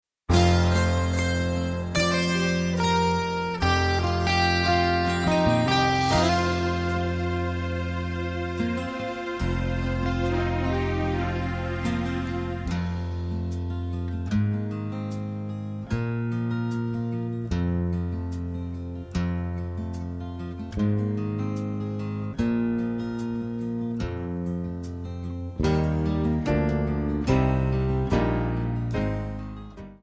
D
MPEG 1 Layer 3 (Stereo)
Backing track Karaoke
Country, 1990s